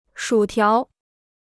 薯条 (shǔ tiáo) Kostenloses Chinesisch-Wörterbuch